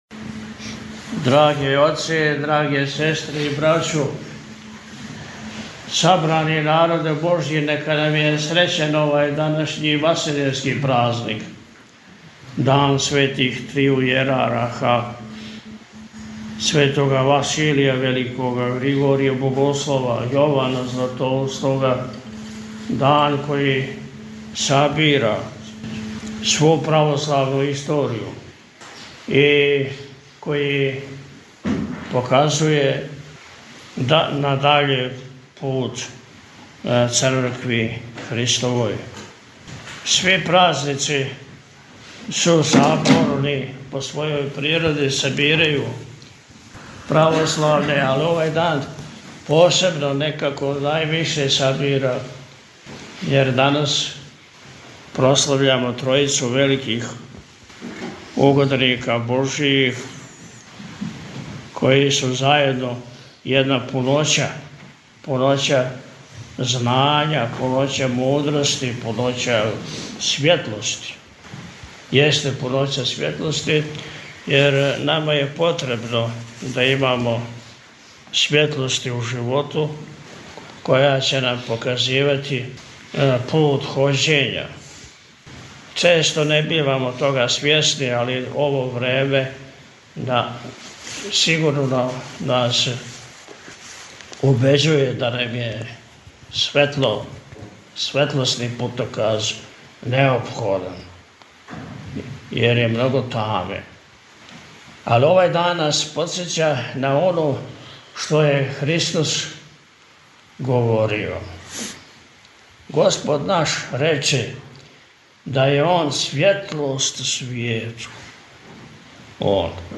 Митрополит Атанасије служио у манастиру Свете Тројице код Пљеваља - Eпархија Милешевска
Beseda-Pljevlja-Manastir-2.mp3